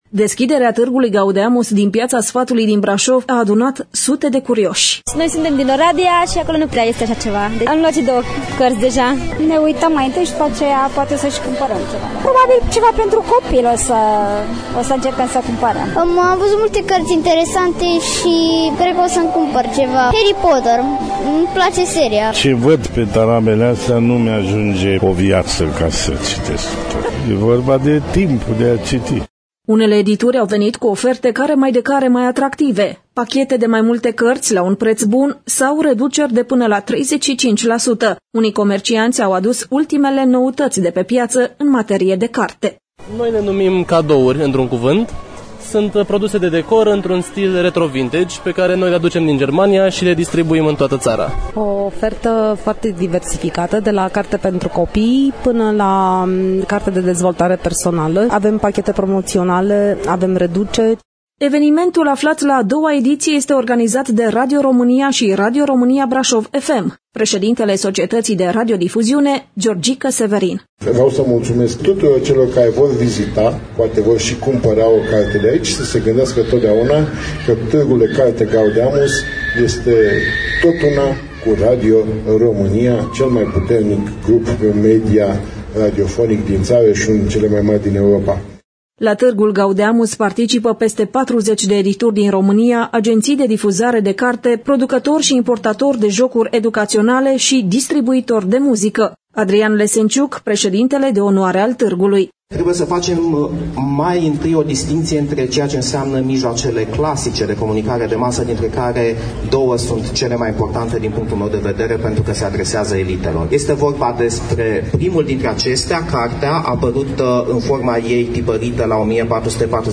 Colegii noştri de la Radio România Braşov FM au surprins atmosfera de ieri, de la deschiderea evenimentului: